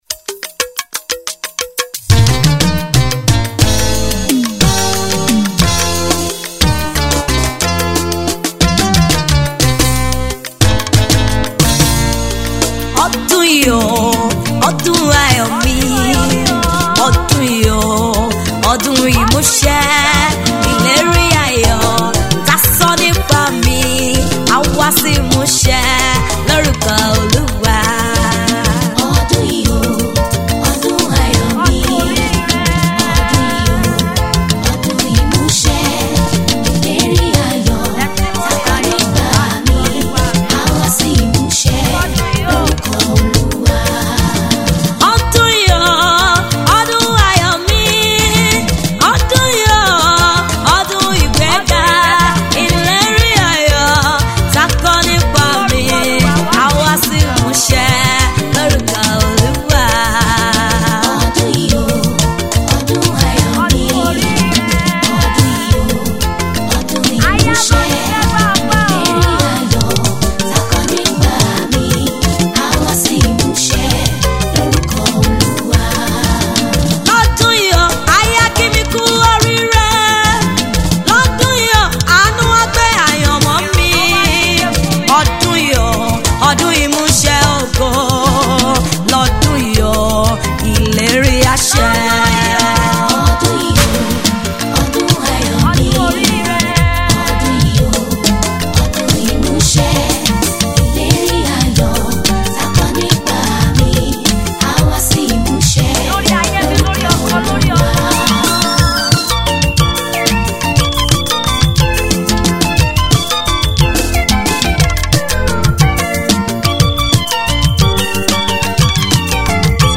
Gospel
prophetic song